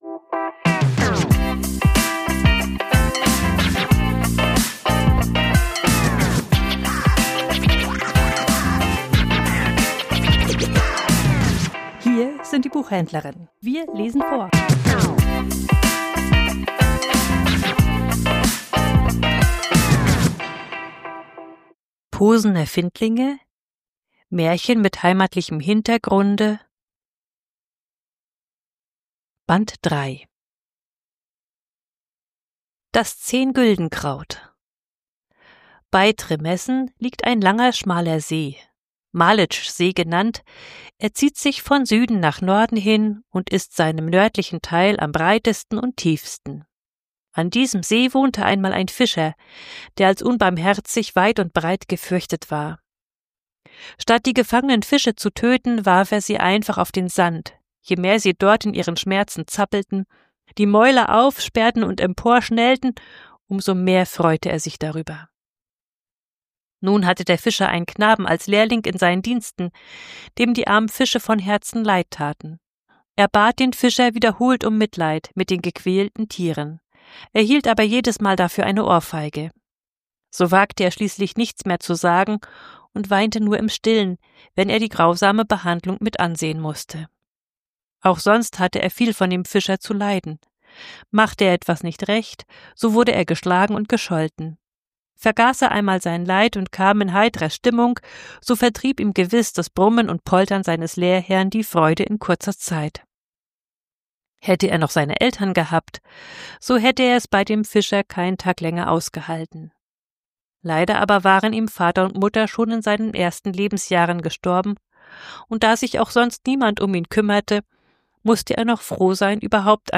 Vorgelesen: Das 10 Güldenkraut ~ Die Buchhändlerinnen Podcast